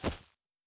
Tree Hit_3.wav